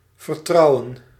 Ääntäminen
IPA: /truː/